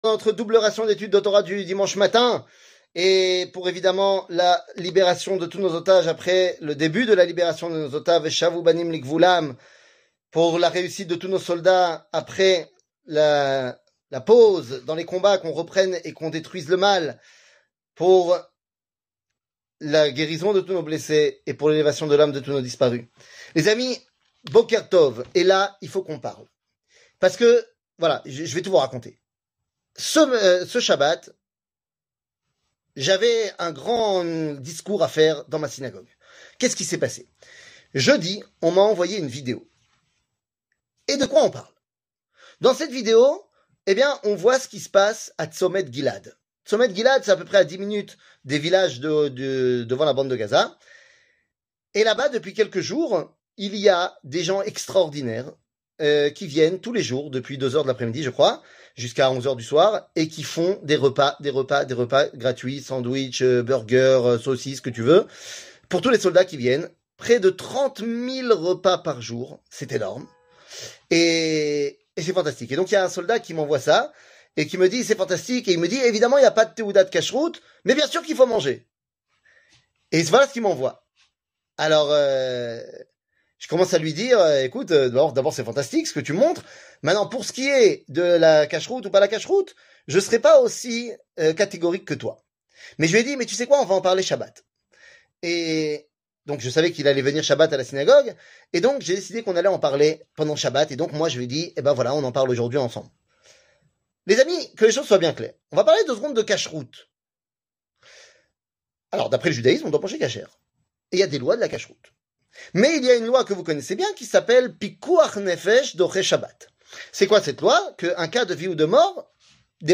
שיעור מ 26 נובמבר 2023 11MIN הורדה בקובץ אודיו MP3